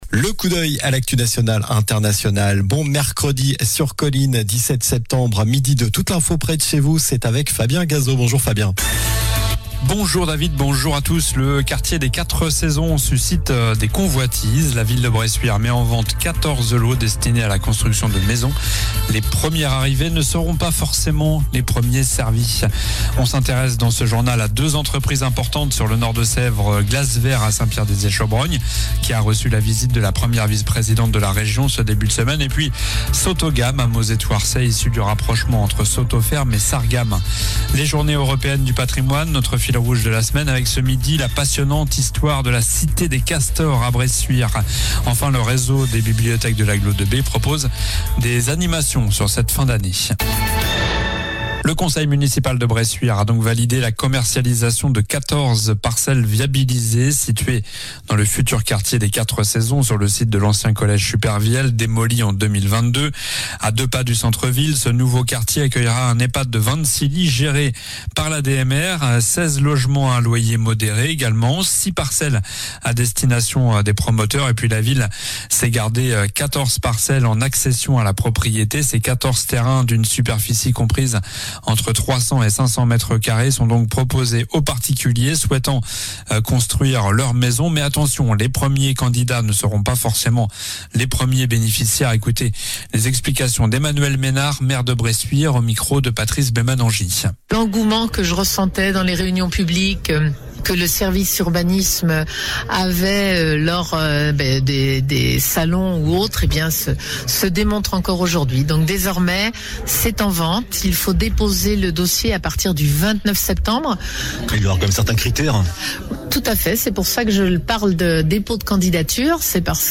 Journal du mercredi 17 septembre (midi)